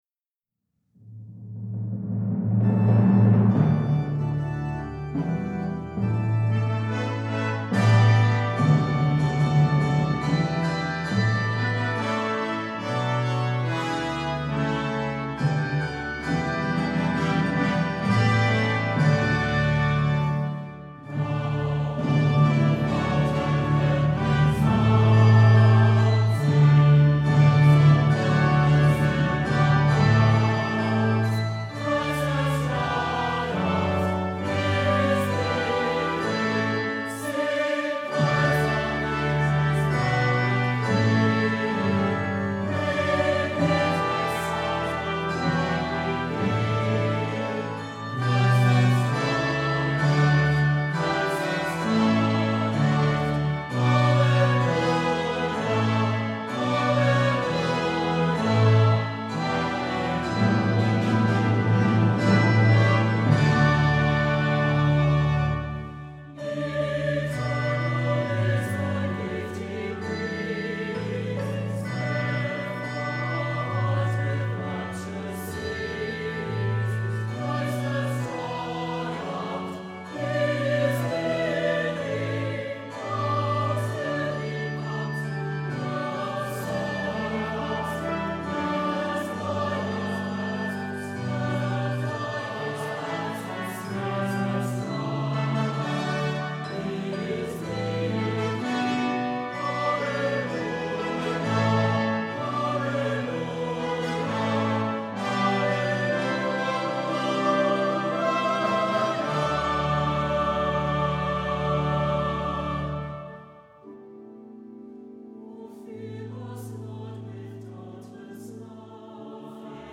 Voicing: SATB and Congregation